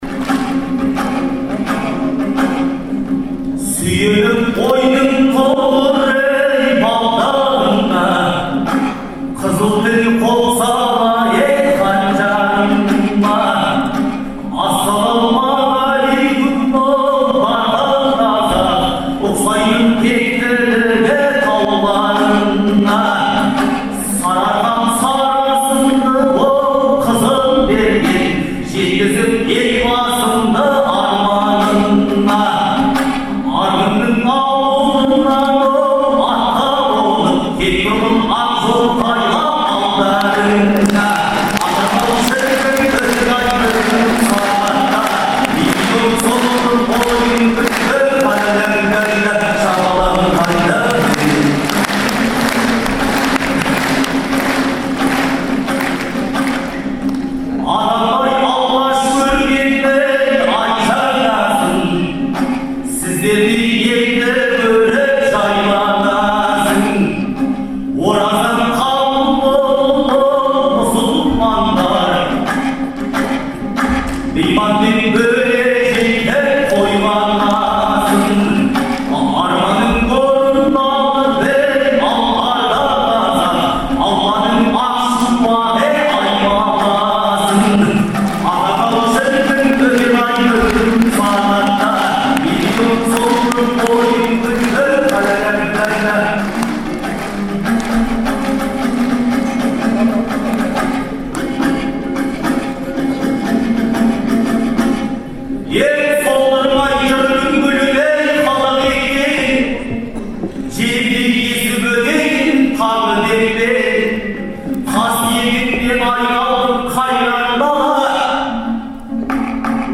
Шілденің 8-9-ы күндері Астанадағы «Қазақстан» орталық концерт залында «Ел, Елбасы, Астана» деген атпен ақындар айтысы өтті. Алғашқы күні айтысқан 20 ақынның арасынан іріктеліп шыққан он адам екінші күні бес жұп болып шықты.